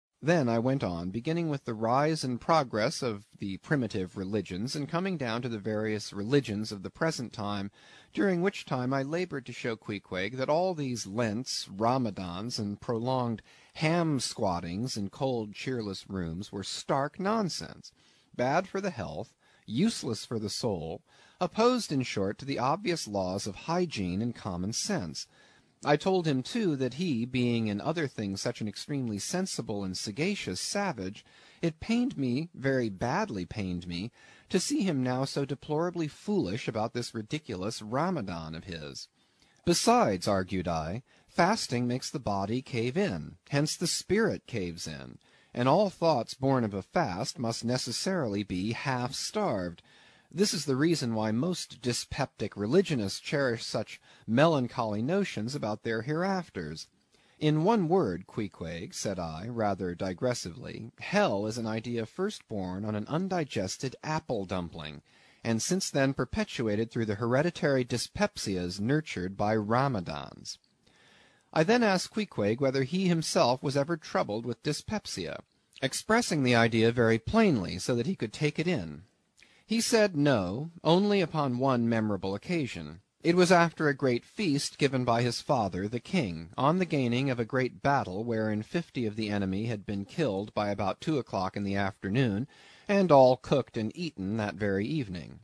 英语听书《白鲸记》第305期 听力文件下载—在线英语听力室